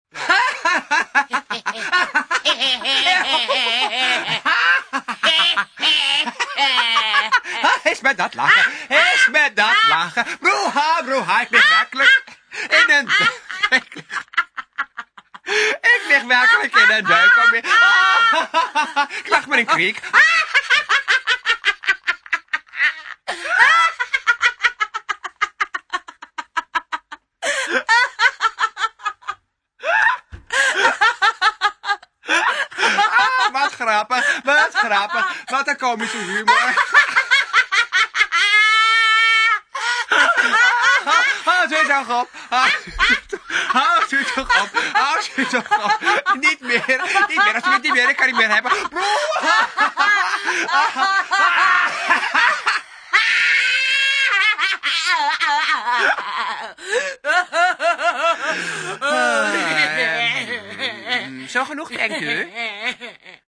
deze lachband nodig.
Elke keer als ik 'm hoor lach ik mij werkelijk een kriek.
lachband.mp3